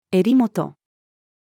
襟元-female.mp3